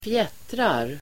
Uttal: [²fj'et:rar]